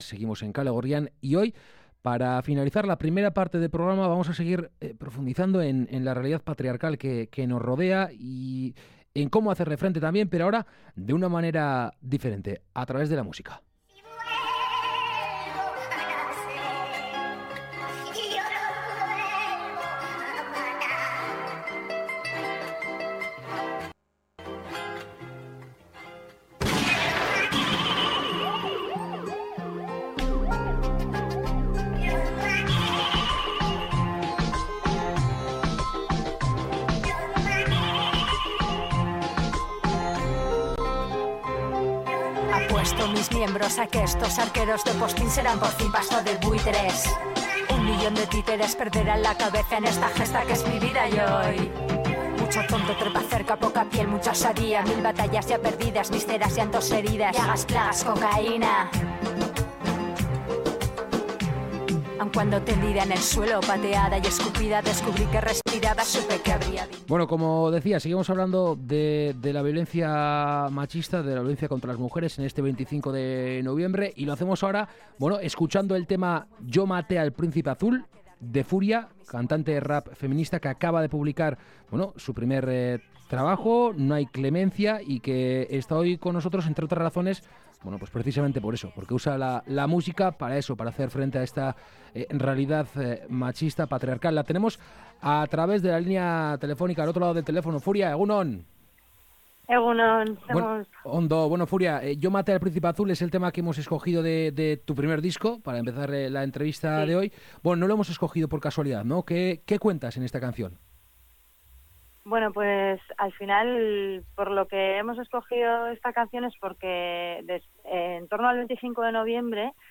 Hoy toca en el Doka Kafe Antzokia de Donostia a partir de las 21:00 horas y antes ha estado en Info7 Irratia, presentándonos algunas de sus canciones.